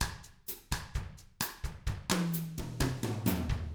129BOSSAF2-R.wav